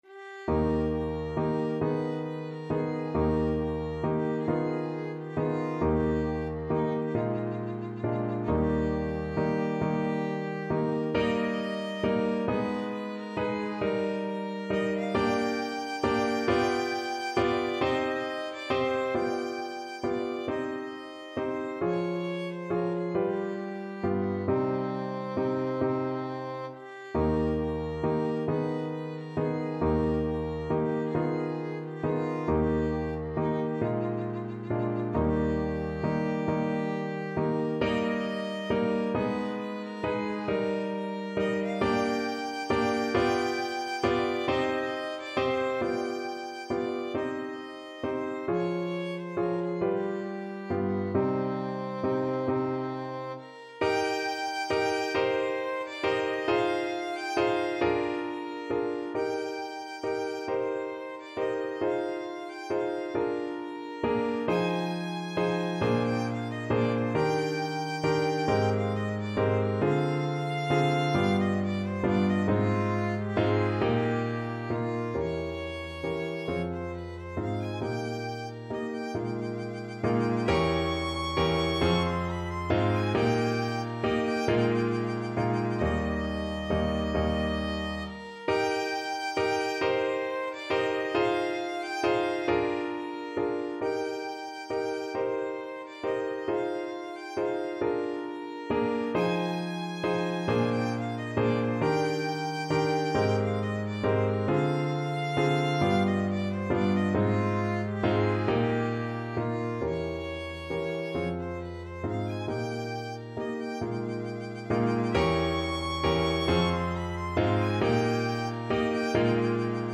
Violin
Eb major (Sounding Pitch) (View more Eb major Music for Violin )
Andantino .=c.45 (View more music marked Andantino)
6/8 (View more 6/8 Music)
Classical (View more Classical Violin Music)